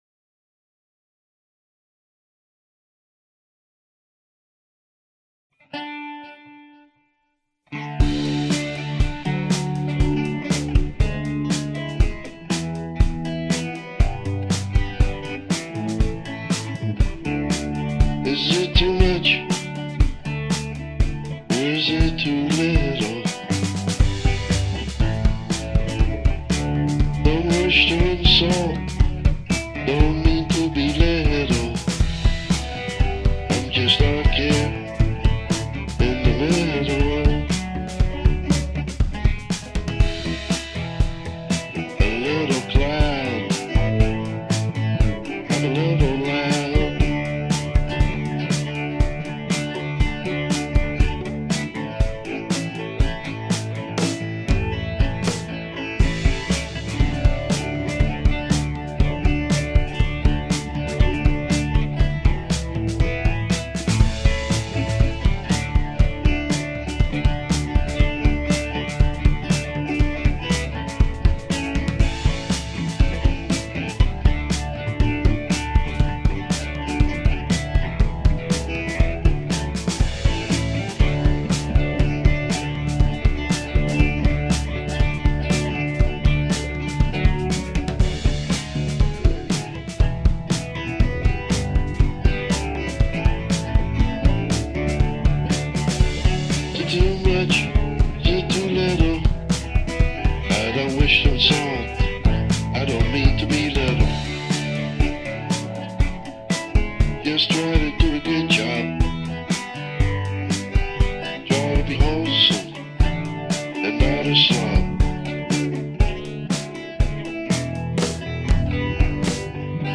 drum programing, bass, guitar
vocals, guitars